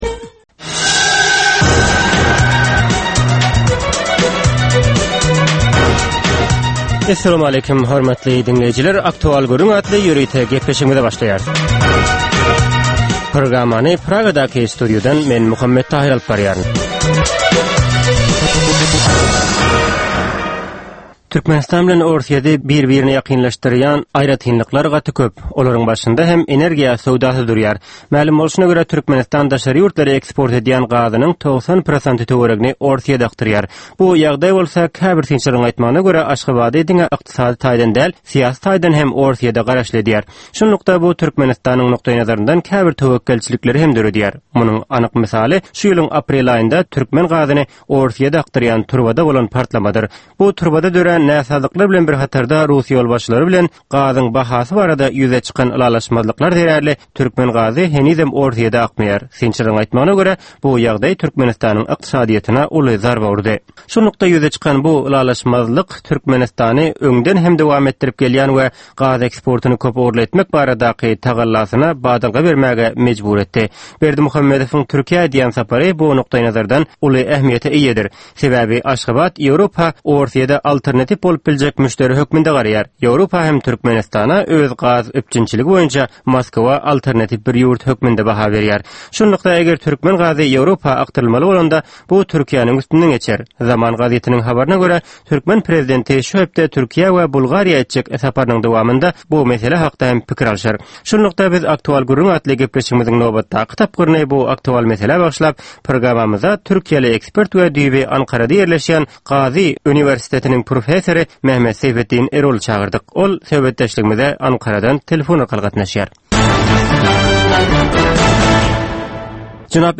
Hepdänin dowamynda Türkmenistanda ýa-da halkara arenasynda ýüze çykan, bolup geçen möhüm wakalar, meseleler barada anyk bir bilermen ýa-da synçy bilen geçirilýän 10 minutlyk ýörite söhbetdeslik. Bu söhbetdeslikde anyk bir waka ýa-da mesele barada synçy ýa-da bilermen bilen aktual gürründeslik geçirilýär we meselänin dürli ugurlary barada pikir alsylýar.